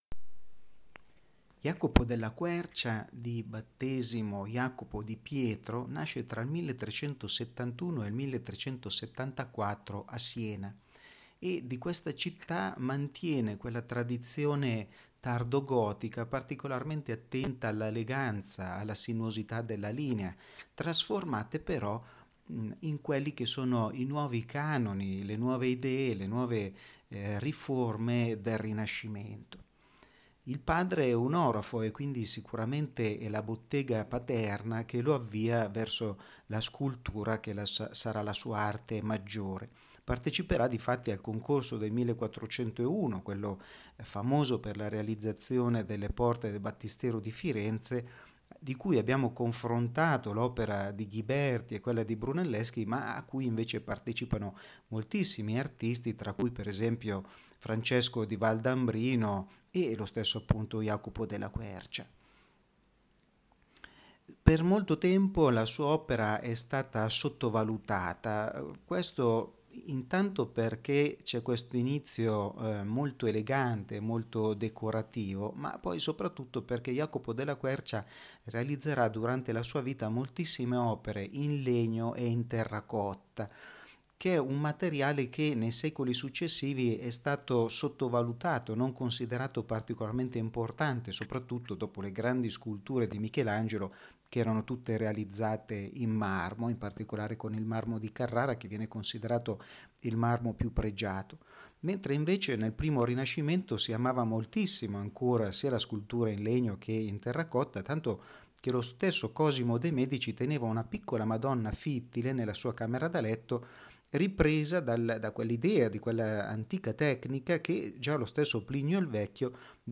Ascolta la lezione audio dedicata a Iacopo della Quercia Fonte Gaia a Siena Acca Larenzia Rea Silvia Ilaria del Carretto Torre palazzo Guinigi a Lucca